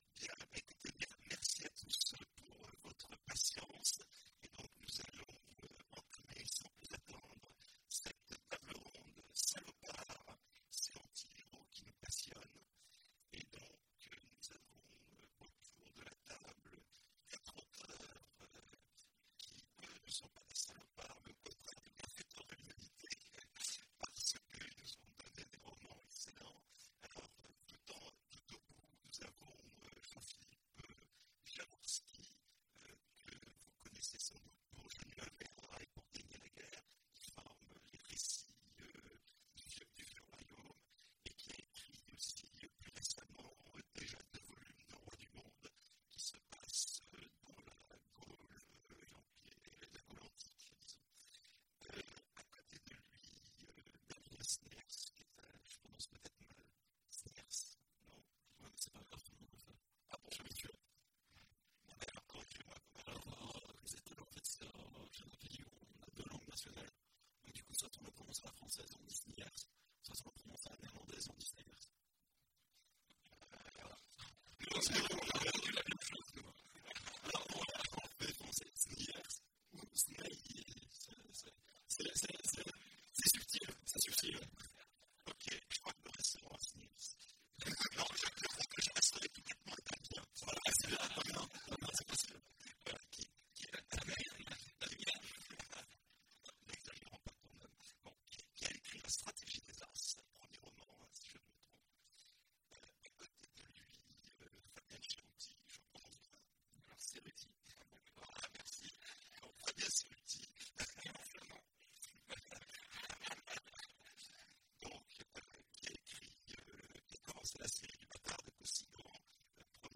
Imaginales 2016 : Conférence Salopards !